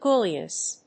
/ˈguljʌs(米国英語), ˈgu:ljʌs(英国英語)/